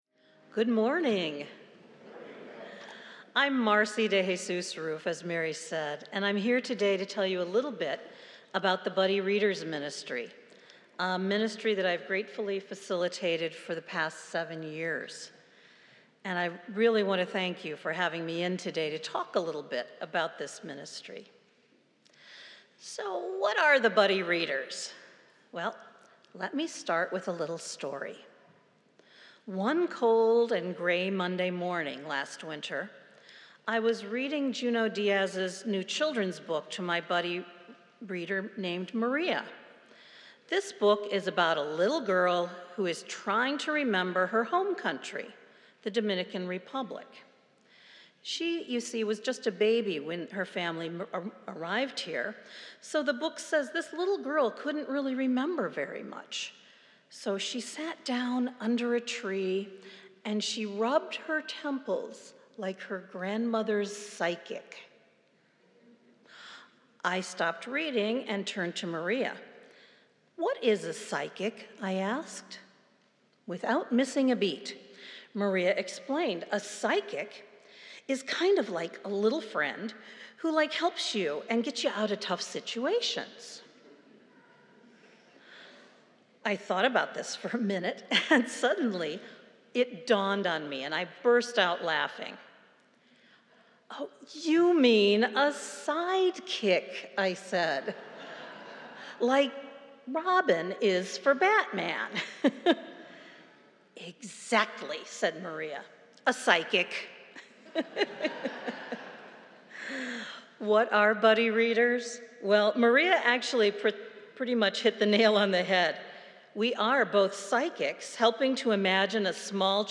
The Spiritus teens also make an appearance as they head off to their mission trip!